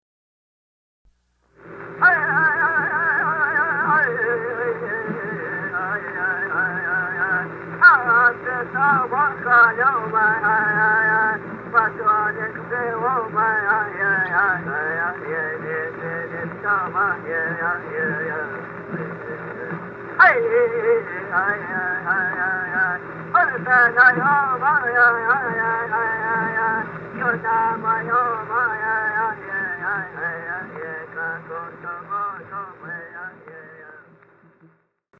Sun Dance Song
Gleichen, Alberta, ca. September 17, 1939.